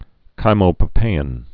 (kīmō-pə-pāĭn, -pīĭn)